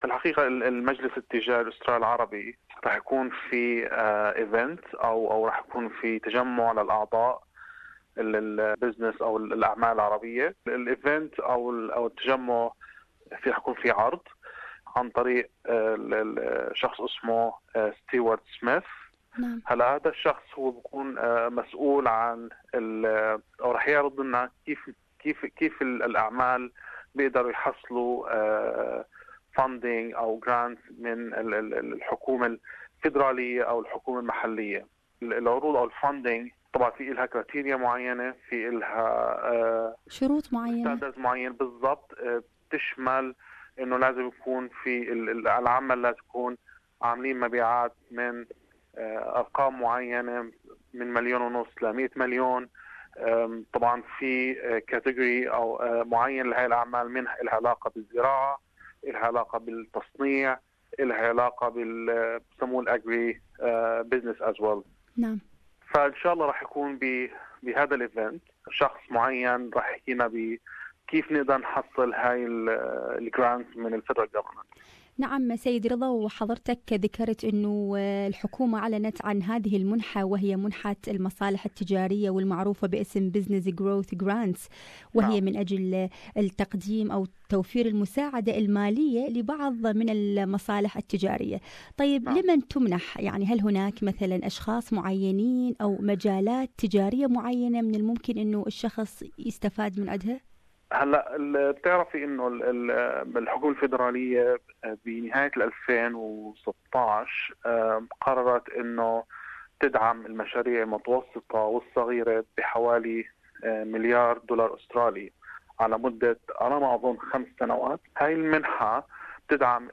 As a small business in Australia, government grants, loans and financial assistance programs are available to help entrepreneurs fund their way to success. The available funding programs can help you fund renovations, purchasing of equipment, hiring staff and training as well as cover advertising expenses and much more. Listen to this interview